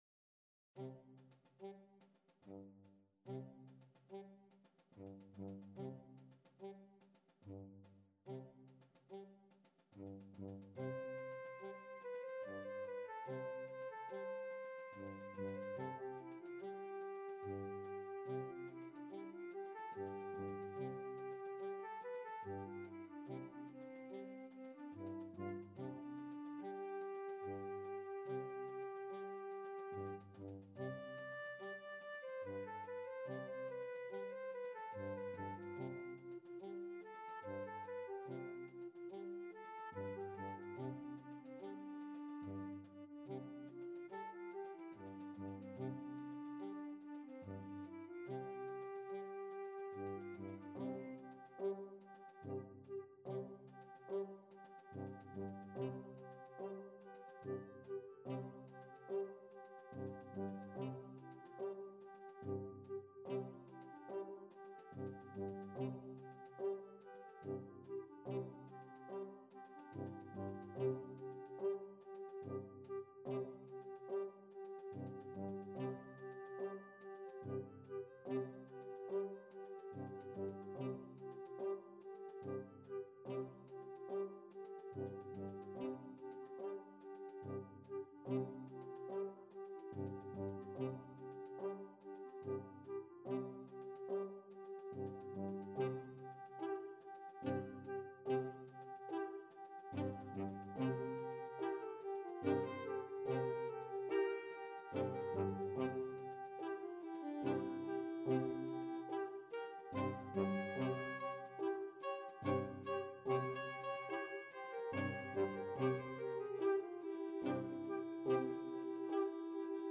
Orchesterstück